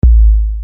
Drum1-mp3.mp3